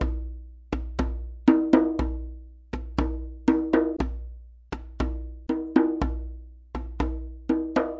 Westafrican Drumensemble " djembe grooves kassa2 120bpm
描述：这是我在我的djembe上演奏的基本Kassarhythm。记录在我的家里。
Tag: 韵律 打击乐器 非洲鼓 非洲 加纳